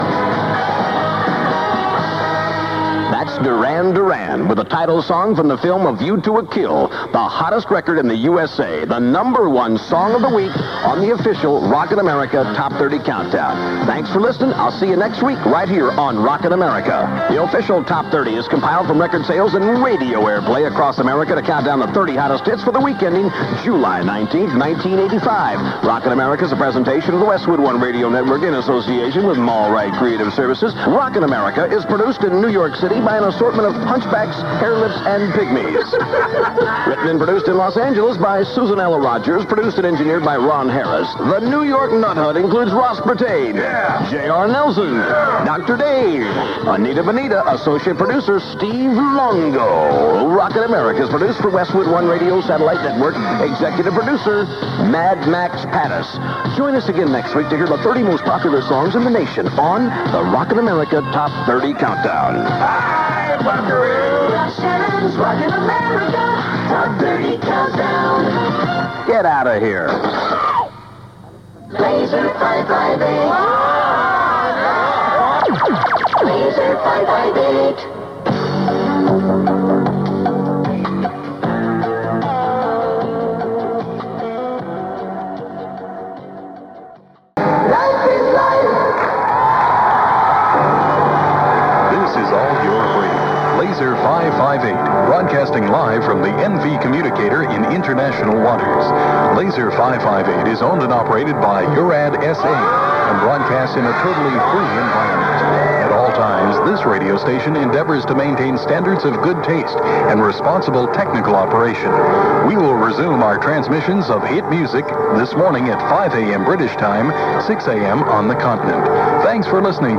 click to hear audio Scott Shannon ending the Rockin' America Top 30 Countdown on Laser-558 at about 00:40am on 29th July 1985.